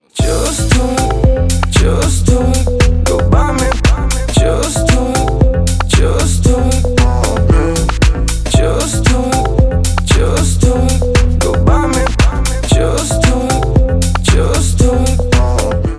• Качество: 128, Stereo
dance